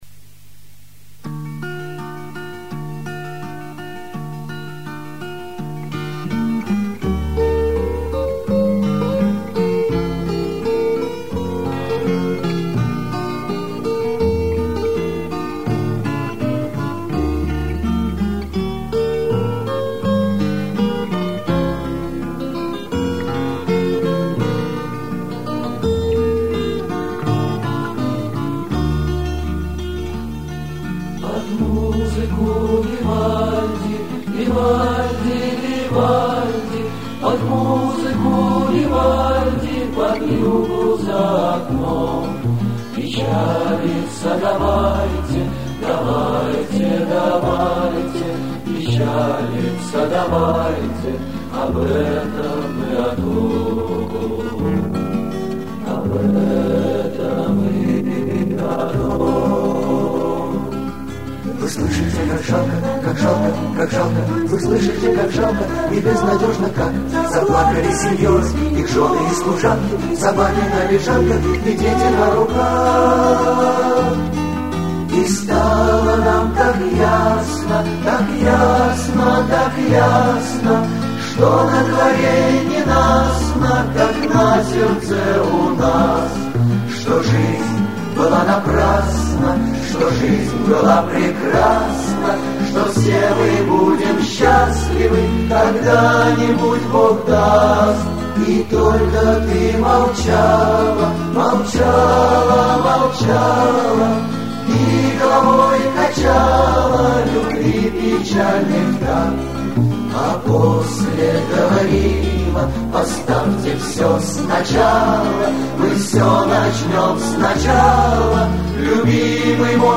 старая добрая песня
ретро